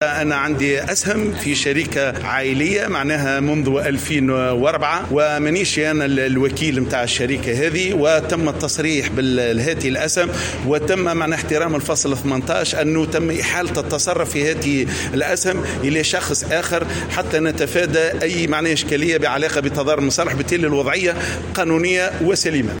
وأشار الشواشي على هامش ندوة للمديرين الجهويين لأملاك الدولة ،إلى وجود نيّة لتعديل قرار كراء الشباب لمقاسم فلاحية من أجل دعمهم بالتمويل والتكوين ،معتبرا أن كراء أراضي بور للشباب هو إهدار للرصيد العقاري الوطني.